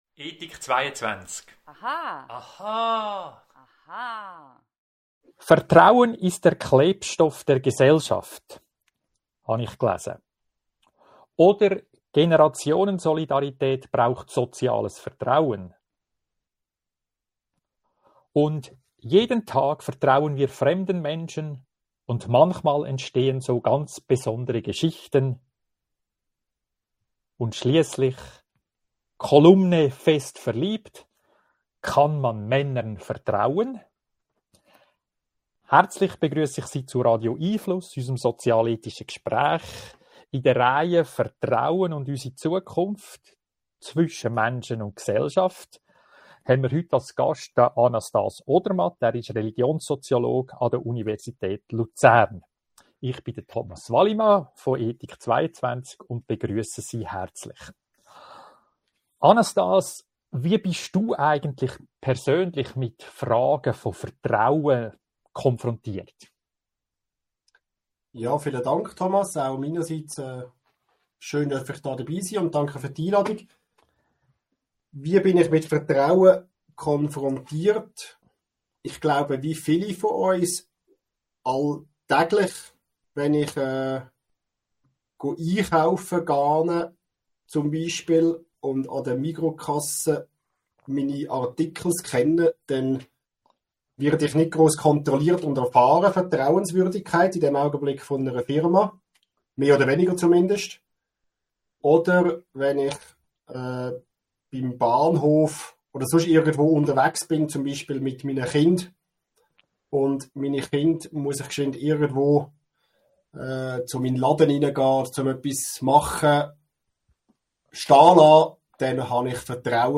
Bleiben Sie über die kommenden Radio🎙einFluss Audio-Gespräche informiert!